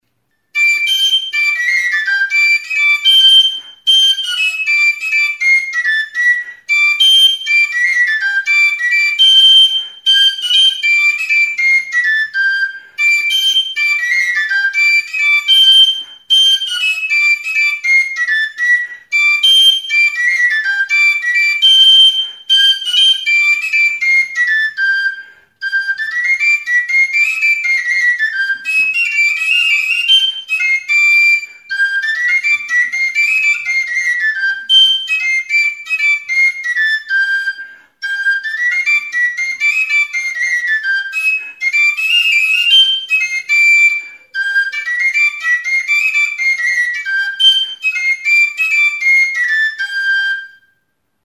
Aérophones -> Flûtes -> Á Bec (á une main)
Enregistré avec cet instrument de musique.
Hiru zuloko ezpelezko flauta zuzena da.